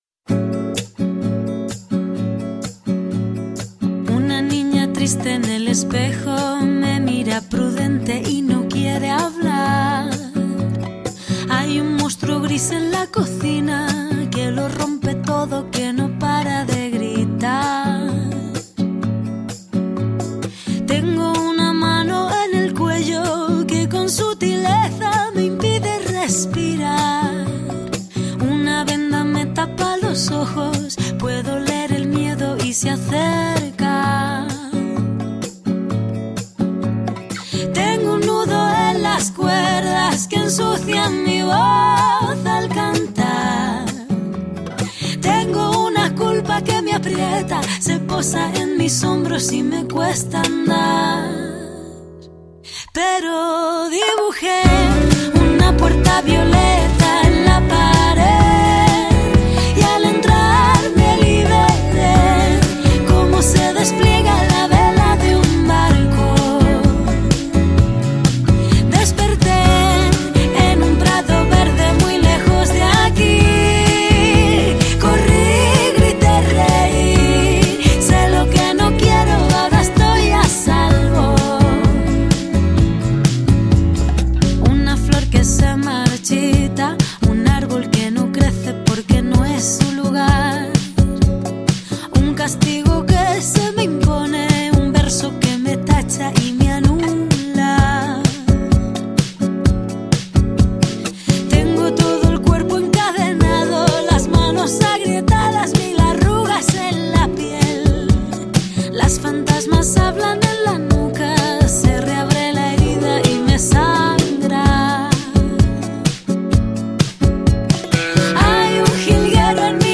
Gaurkoan hori egin dugu eta 14 emakumek bidali dute euren ekarpena. Sistema arrakalatzearen beharra nabarmena dela argudiatu eta mobilizatzera deitu gaituzte gaurkoan.